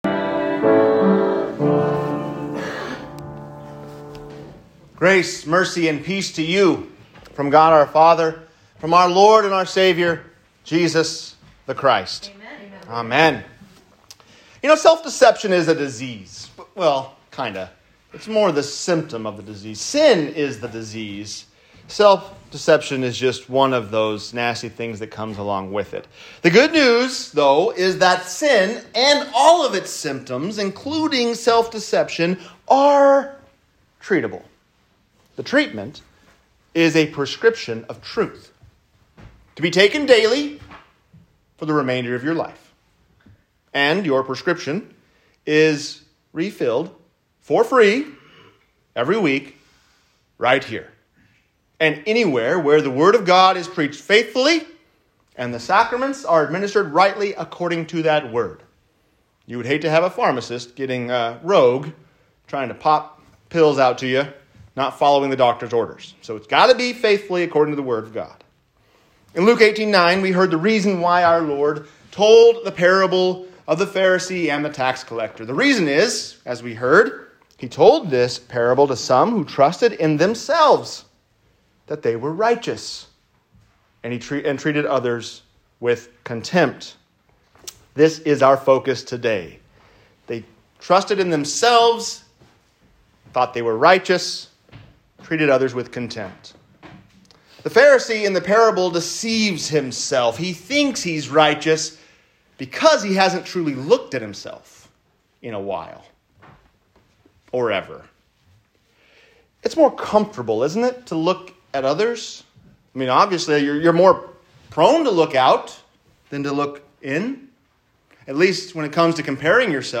Truth is Powerful Medicine | Sermon